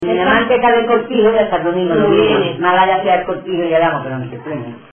Materia / geográfico / evento: Canciones de amor Icono con lupa
Arenas del Rey (Granada) Icono con lupa
Secciones - Biblioteca de Voces - Cultura oral